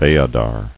[Ve·a·dar]